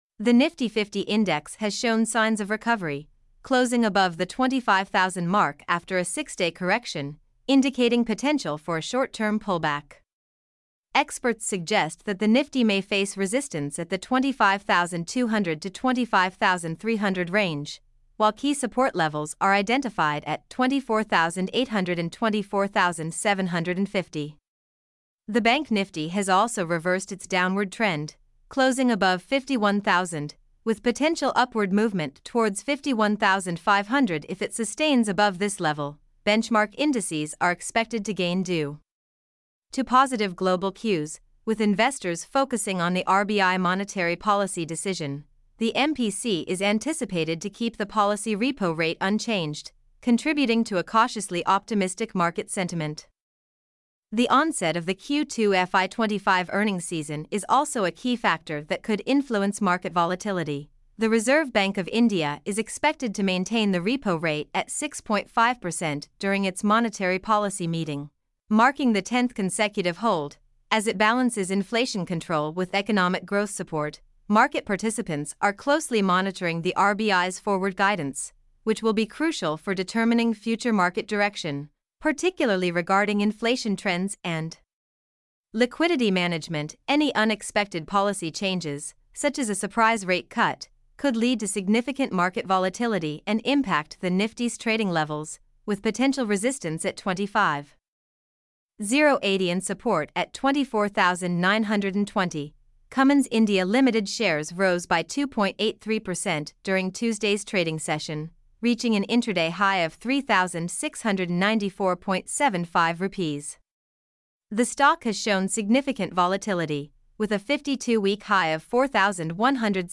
mp3-output-ttsfreedotcom.mp3